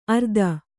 ♪ ardana